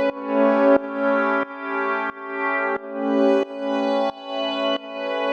GnS_Pad-dbx1:4_90-A.wav